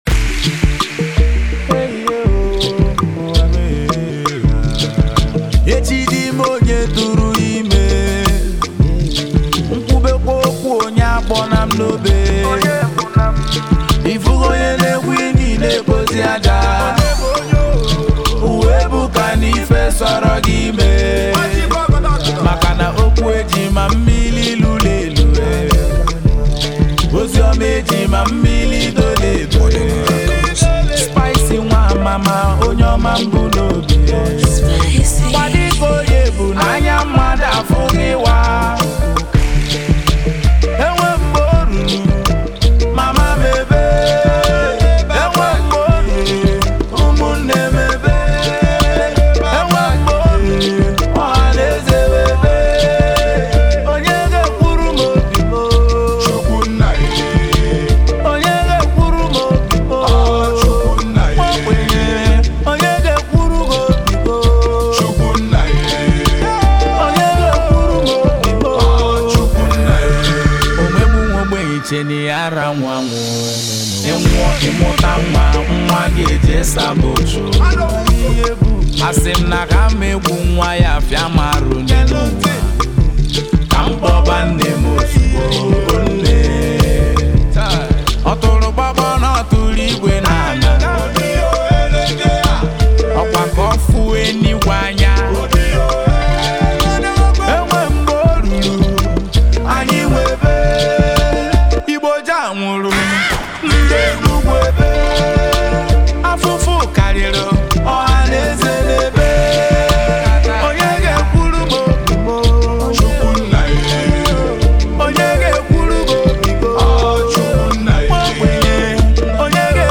Nigerian indigenous music duo